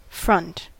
Ääntäminen
US : IPA : [fɹʌnt]